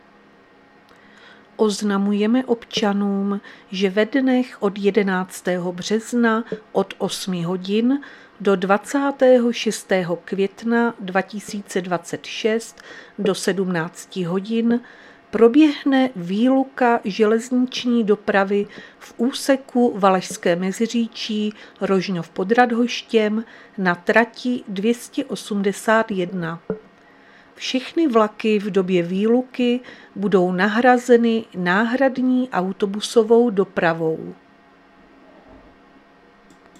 Záznam hlášení místního rozhlasu 10.3.2026